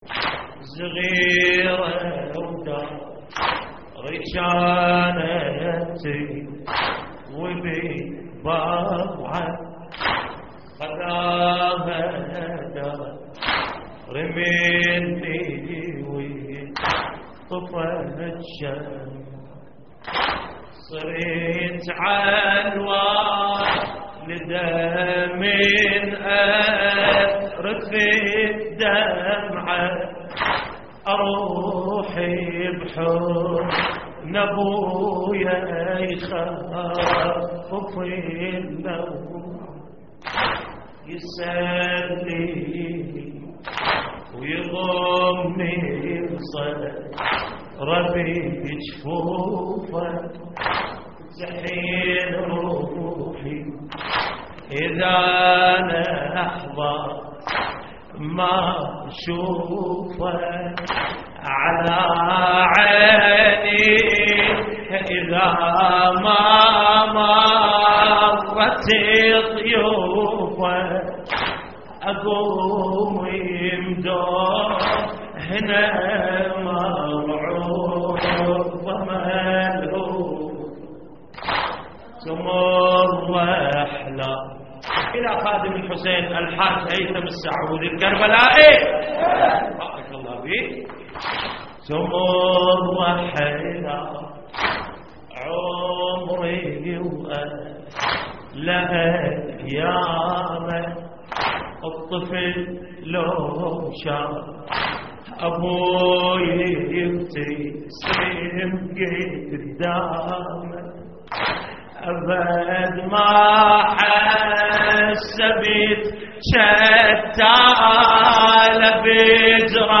اللطميات الحسينية صغيرة وداري كانت تضوي بالبضعة